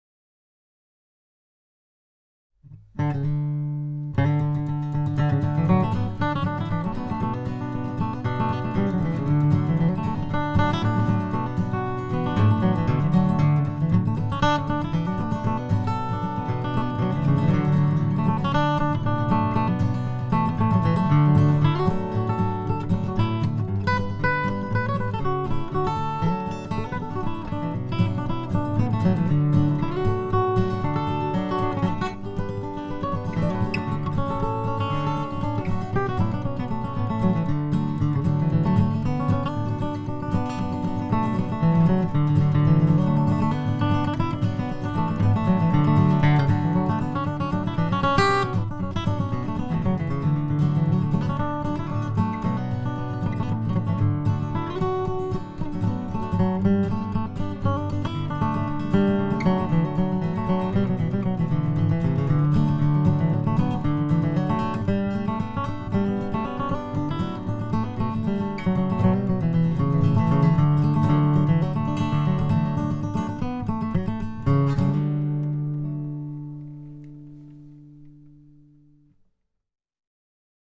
Top 20 Songs flatpicked guitar songs which Flatpicker Hangout members have uploaded to the website.